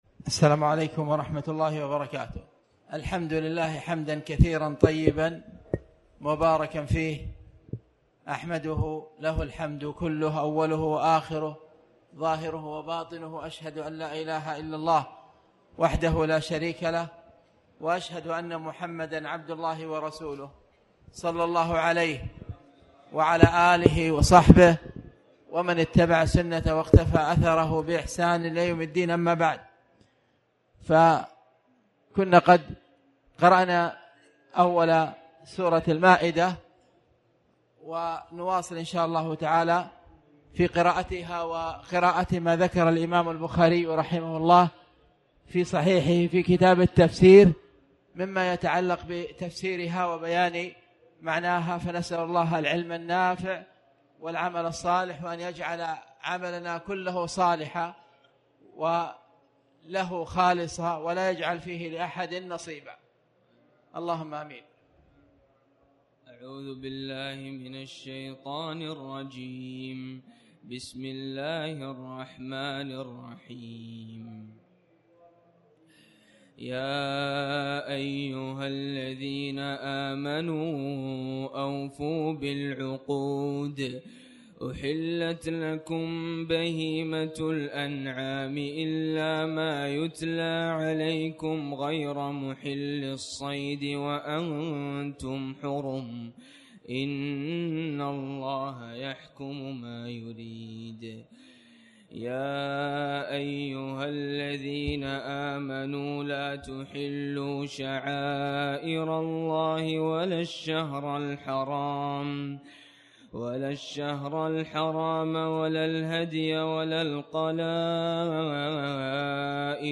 تاريخ النشر ١٤ رمضان ١٤٣٩ هـ المكان: المسجد الحرام الشيخ